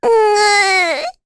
Rehartna-Vox_Sad2_kr.wav